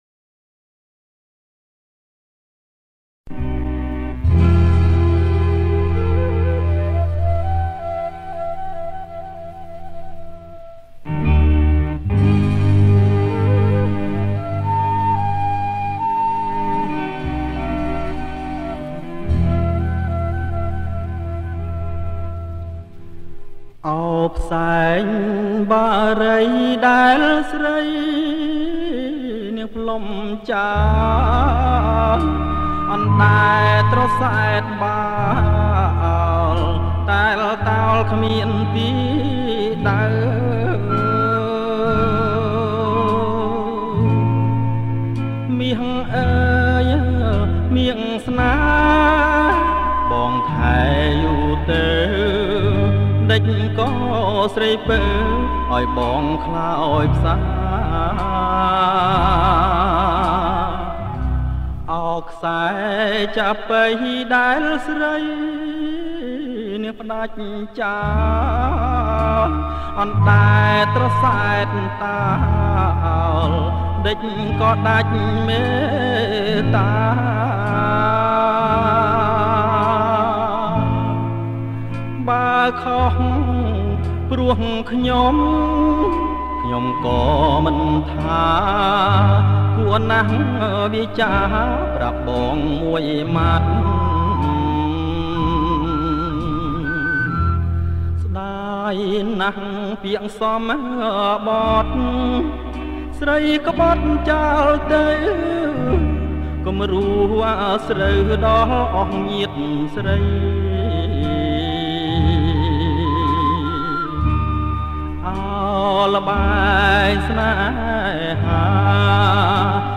• ប្រគំជាចង្វាក់ Slow Folk